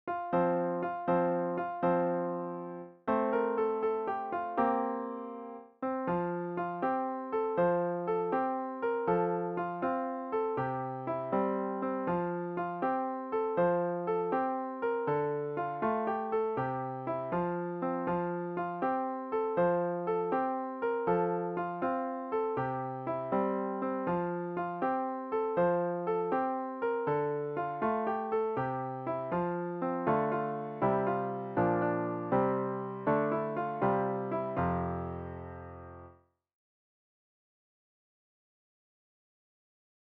Produced digitally in Finale music notation software